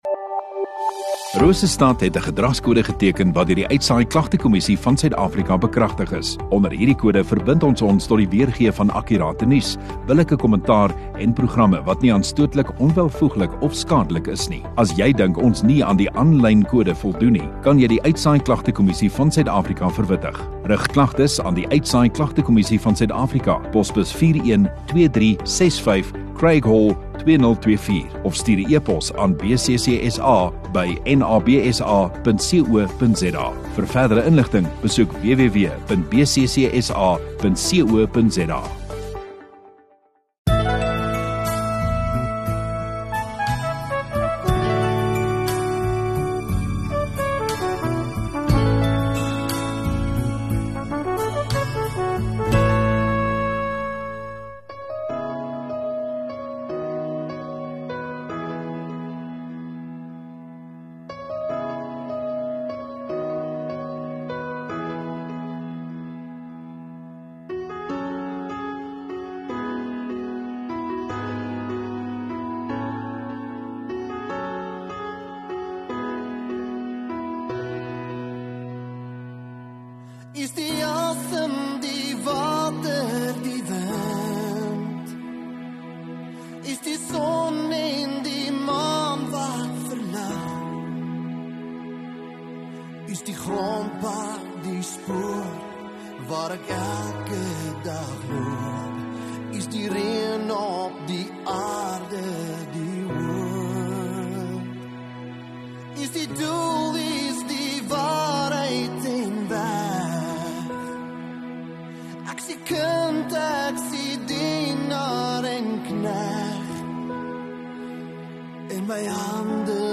19 May Sondagoggend Erediens